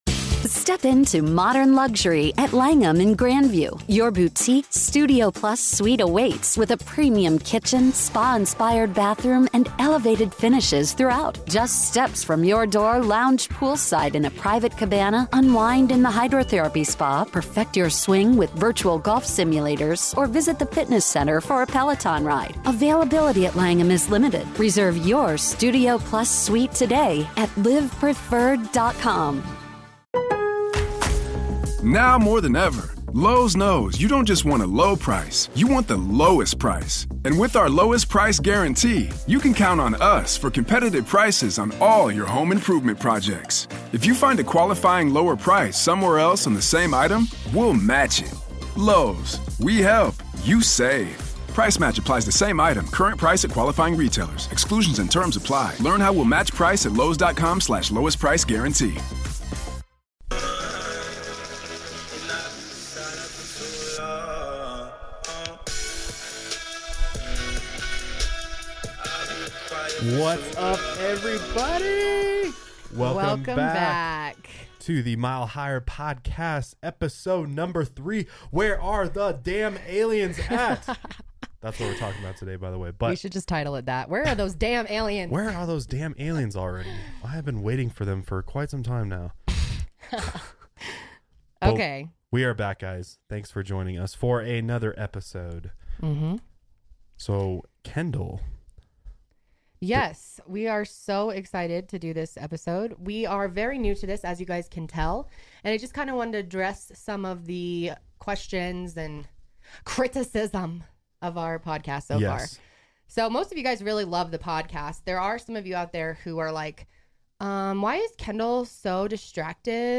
*We had some technical difficulties this week with the audio recording, our apologies it will be fixed*